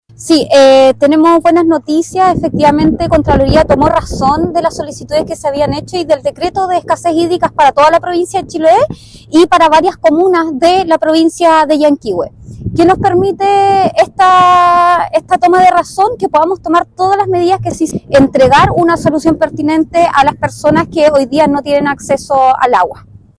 De esta forma confirma la declaración la Delegada Presidencial Regional, Giovanna Moreira, al confirmar la resolución emanada por la Contraloría General.